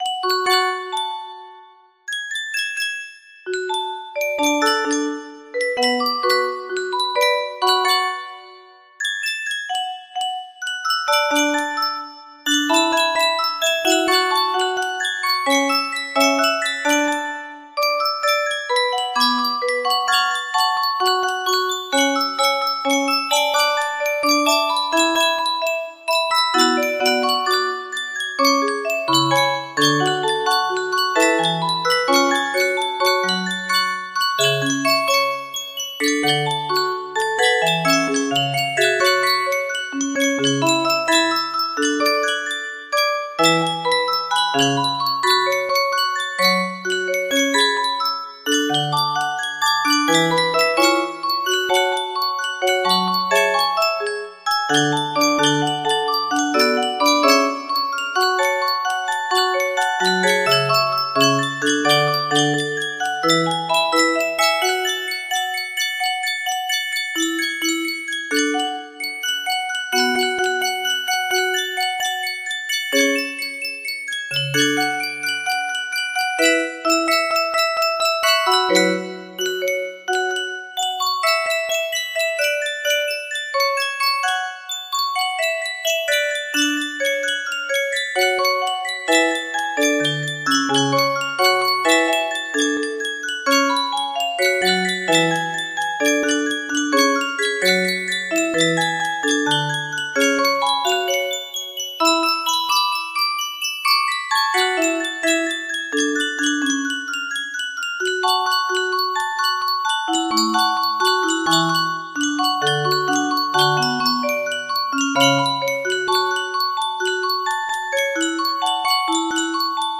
A Journey To Tyria music box melody
Full range 60